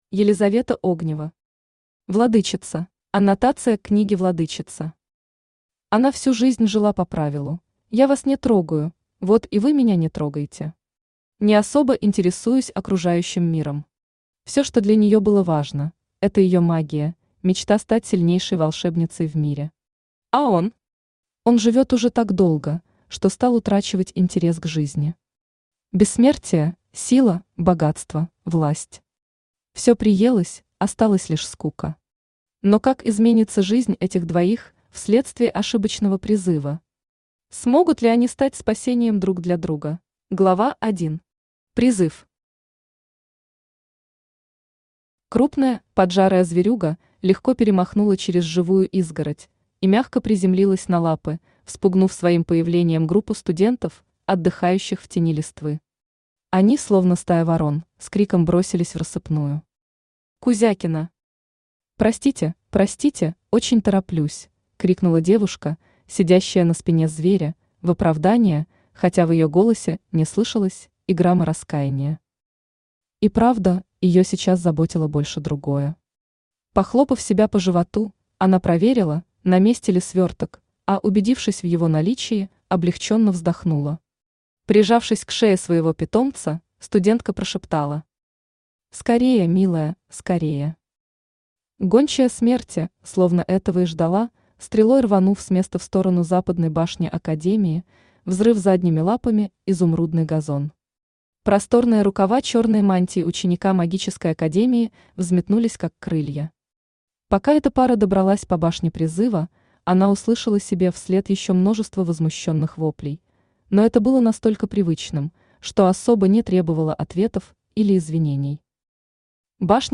Aудиокнига Владычица Автор Елизавета Огнева Читает аудиокнигу Авточтец ЛитРес.